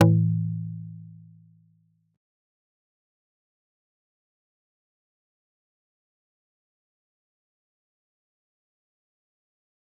G_Kalimba-A2-pp.wav